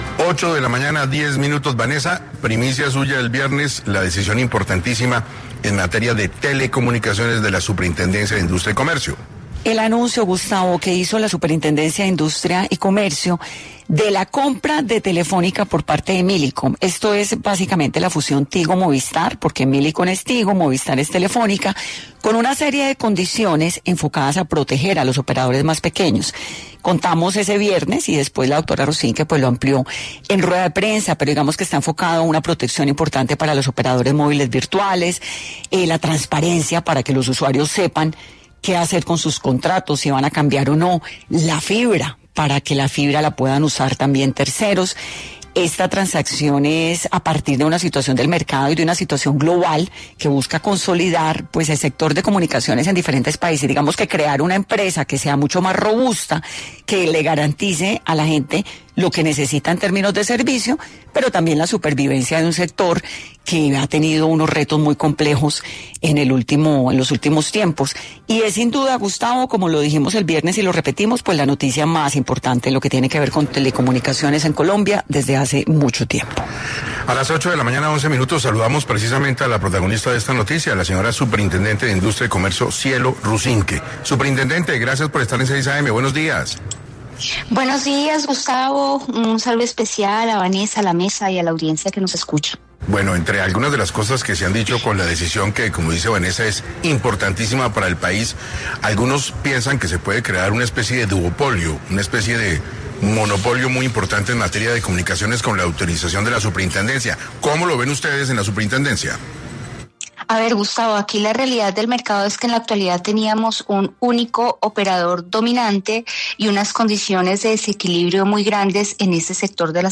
Cielo Rusinque, superintendente de Industria y Comercio, habló en 6 AM de Caracol Radio acerca de la fusión entre Tigo y Movistar